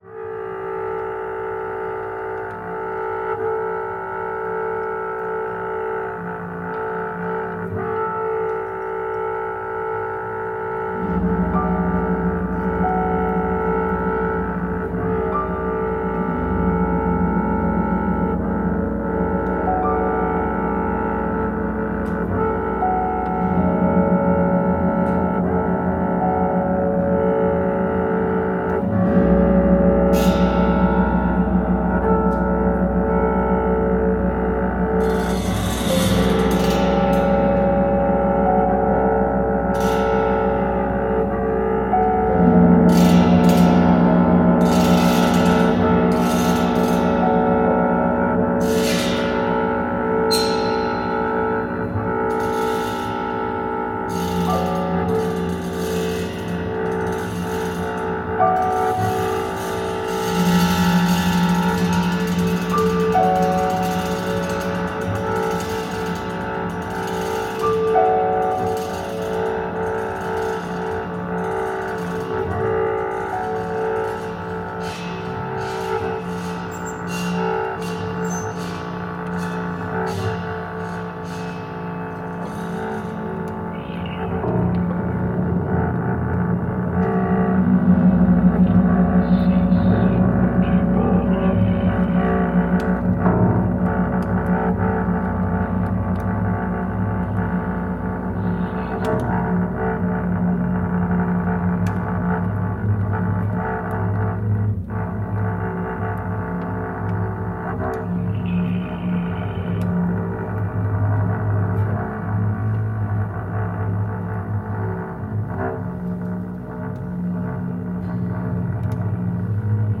piano
contrabass